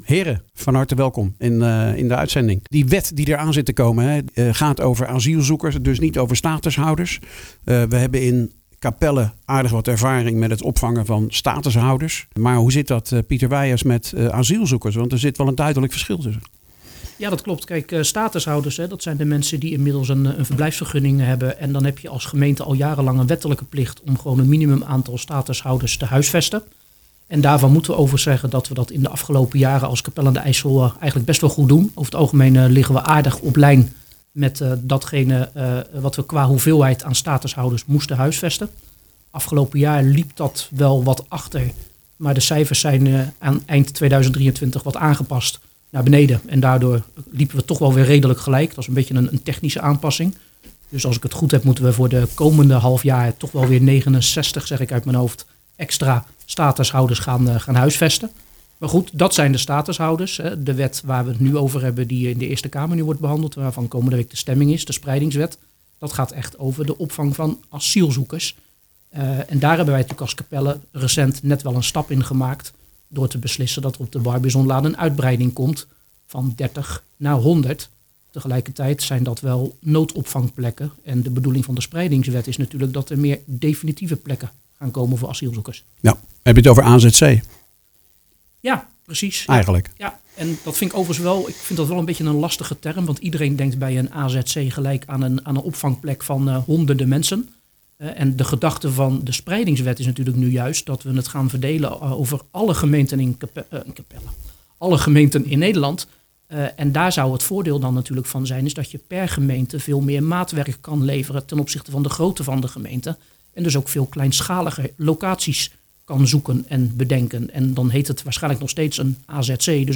met de raadsleden Pieter�Weijers van de SGP en Zinho Schelkers van D66.